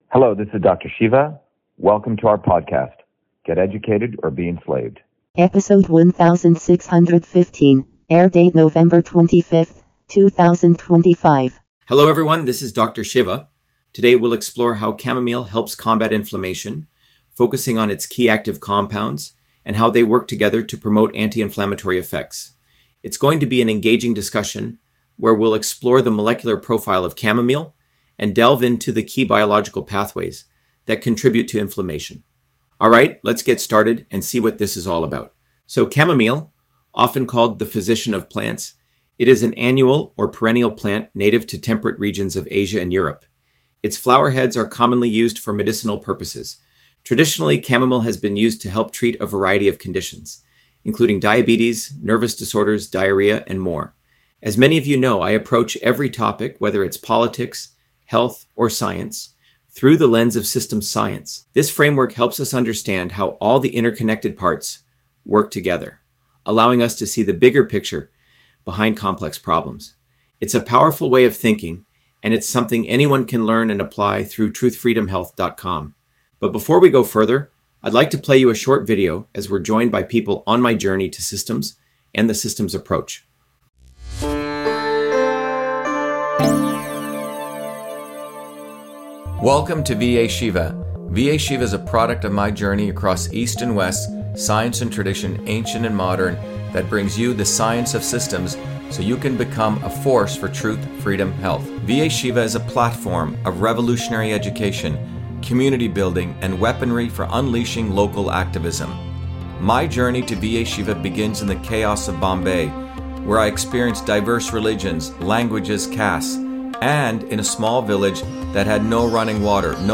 In this interview, Dr.SHIVA Ayyadurai, MIT PhD, Inventor of Email, Scientist, Engineer and Candidate for President, Talks about Chamomile on Inflammation: A Whole Systems Approach